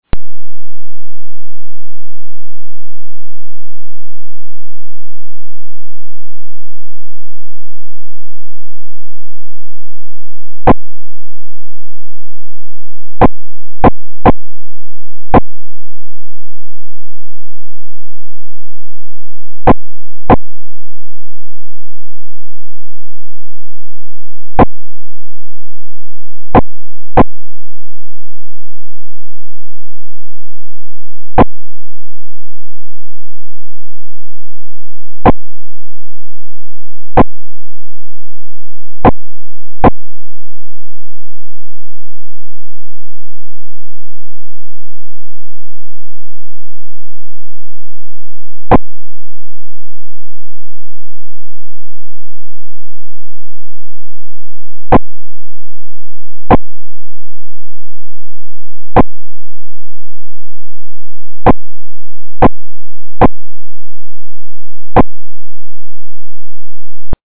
(Following 7-sec. lead-in) ten 1-minute segments,
3. Press the space bar each time you see/hear a flash/noise,